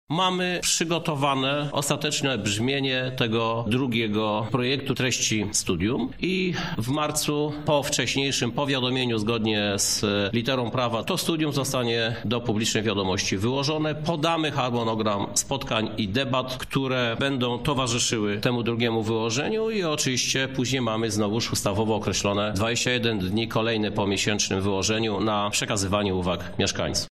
O tym jak wyglądają pracę nad opracowaniem nowego Studium Uwarunkowań i Kierunków Zagospodarowania Przestrzennego mówi prezydent Lublina, Krzysztof Żuk.